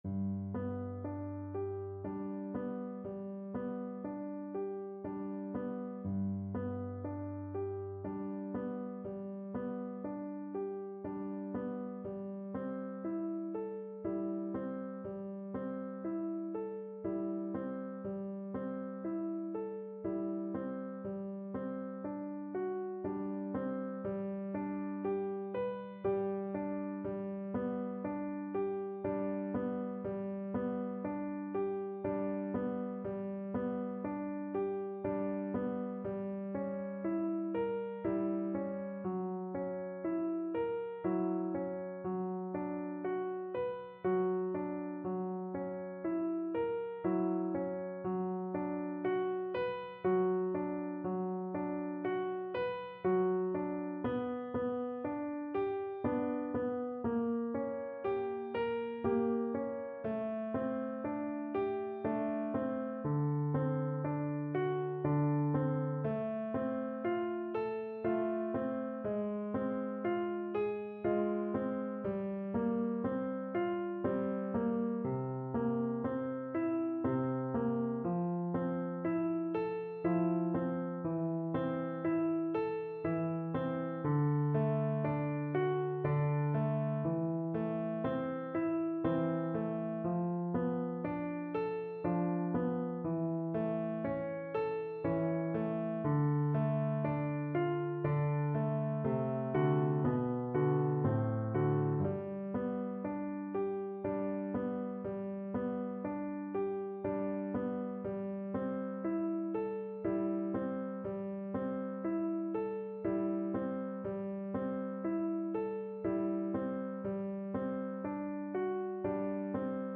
Saint-Saëns: Łabędź (na wiolonczelę i fortepian)
Symulacja akompaniamentu